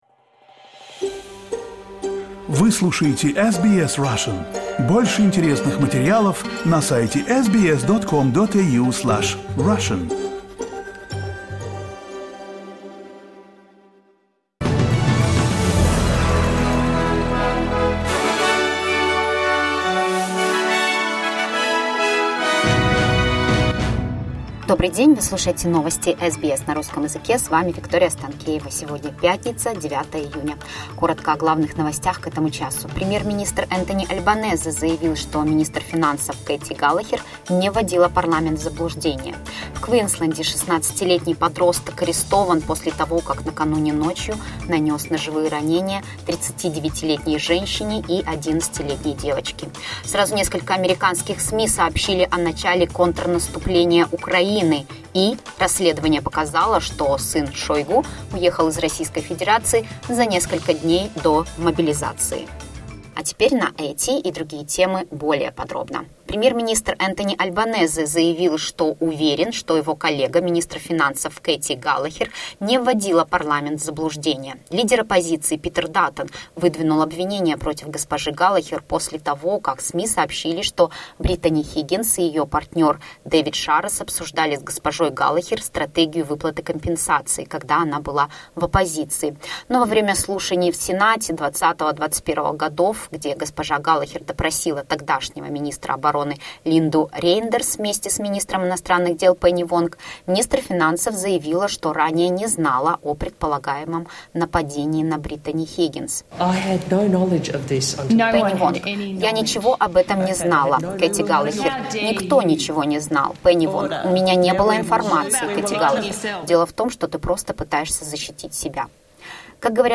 SBS news in Russian — 09.06.2023